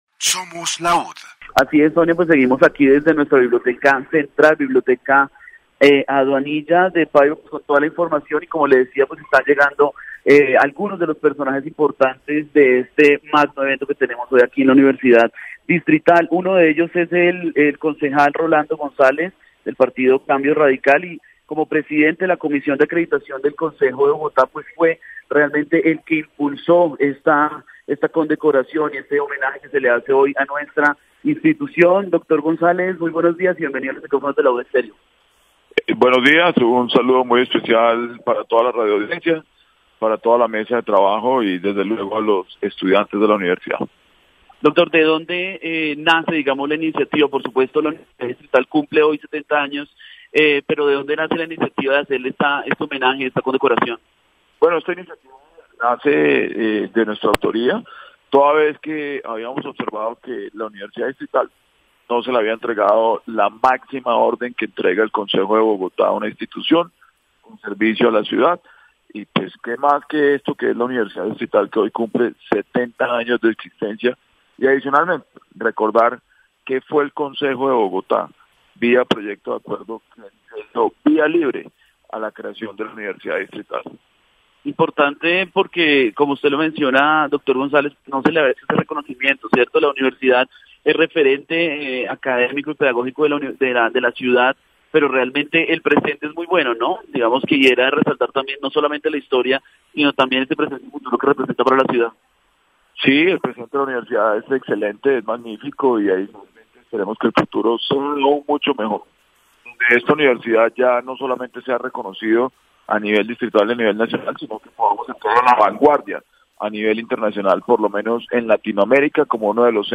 Universidades , Educación superior, Condecoraciones y honores, Programas de radio, Rolando González
Universidad Distrital Francisco Jose de Caldas -- 70 años , Educación -- Superior Bogotá -- Colombia , Programas de Radio , Rolando Gonzalez -- Concejal de Bogota -- Entrevista